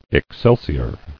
[ex·cel·si·or]